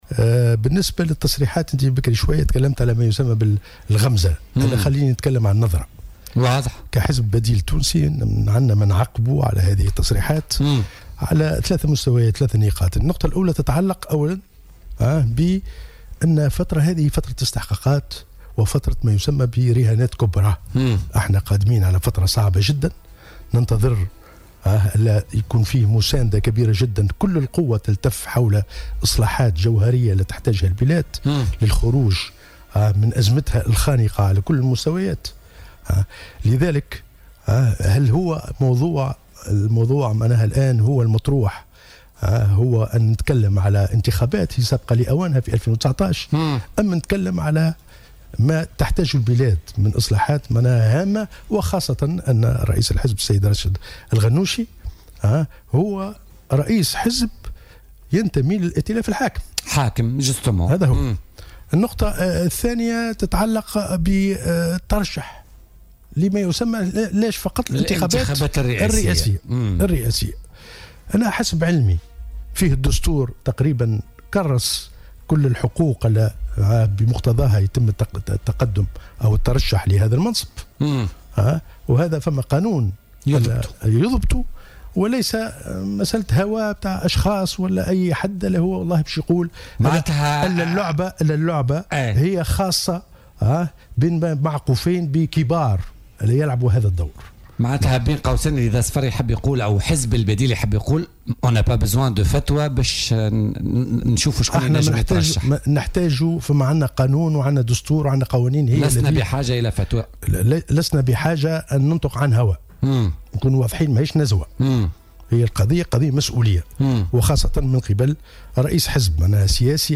وقال في مداخلة له اليوم في برنامج "بوليتيكا" إنه كان من الأولى أن يتطرق رئيس الحركة إلى الرهانات والإصلاحات الكبرى التي تحتاجها البلاد للخروج من أزمتها بدل الحديث عن انتخابات سابقة لأوانها.